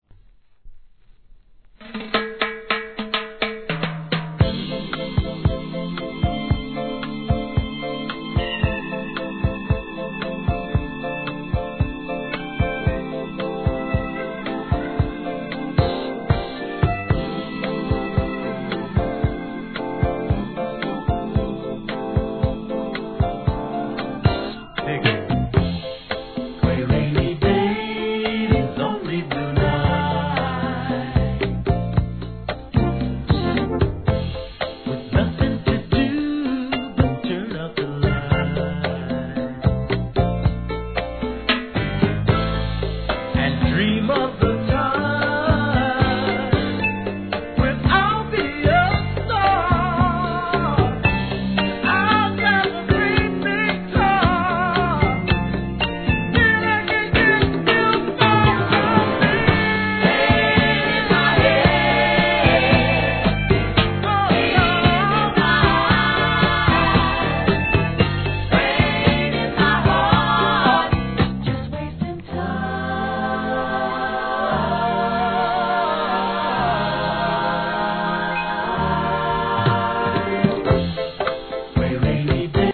SOUL/FUNK/etc...
南国風な爽快メロウチューン